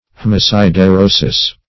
Meaning of haemosiderosis. haemosiderosis synonyms, pronunciation, spelling and more from Free Dictionary.